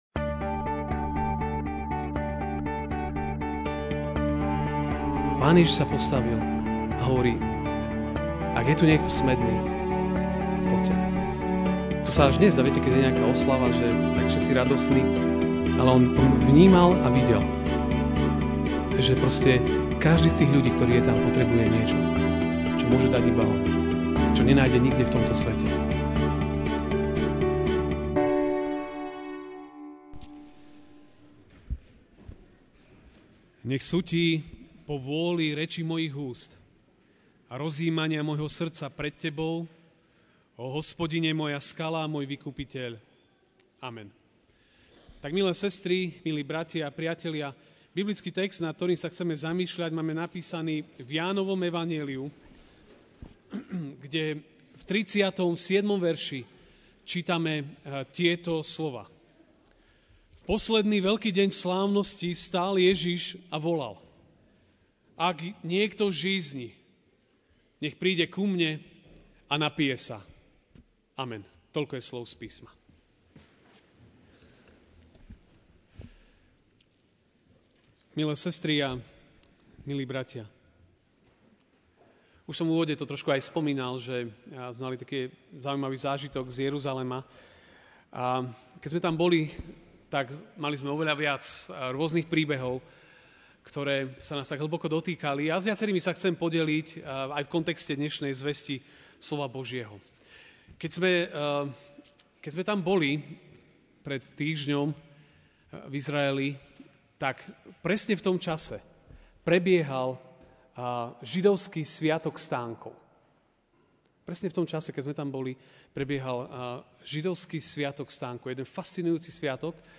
Ranná kázeň: Vďačnosť za Božiu starostlivosť (J 7, 37)V posledný veľký deň slávností stál Ježiš a volal: Ak niekto žízni, nech príde ku mne a napije sa.